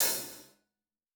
TC Live HiHat 05.wav